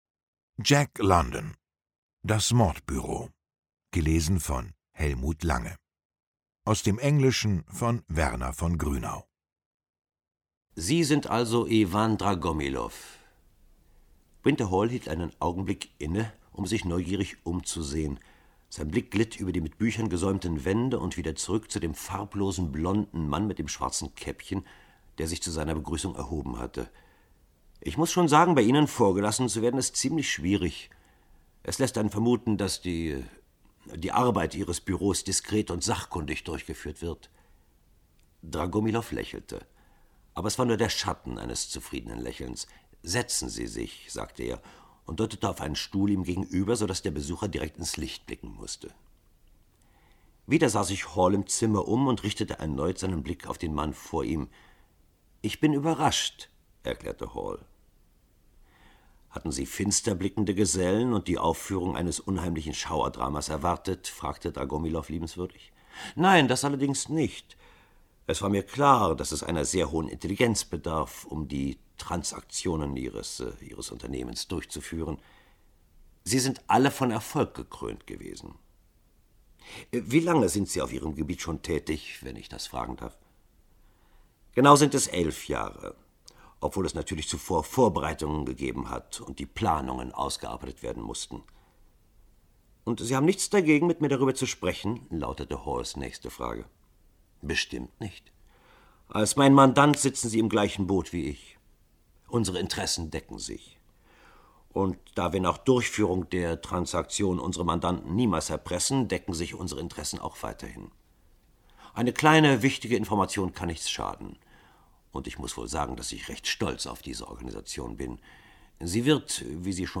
Lesung mit Hellmut Lange (1 mp3-CD)
Hellmut Lange (Sprecher)